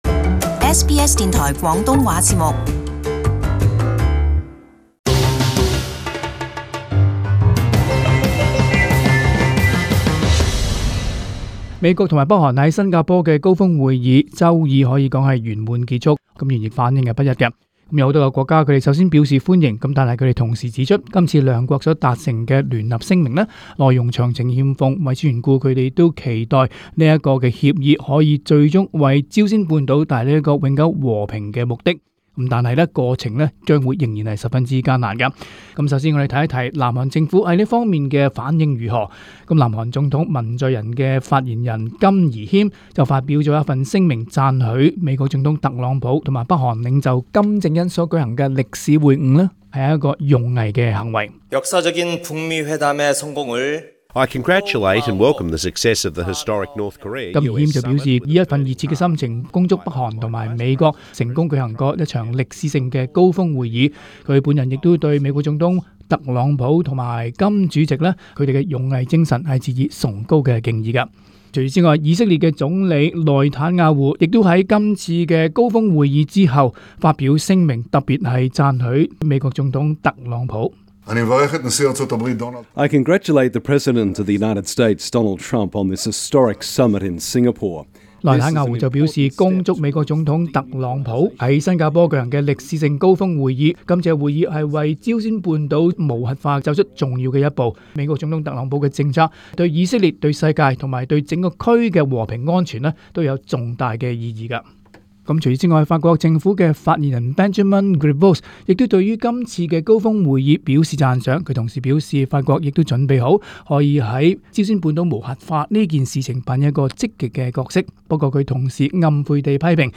【時事報導】特金會毀譽參半